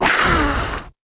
Index of /App/sound/monster/skeleton_wizard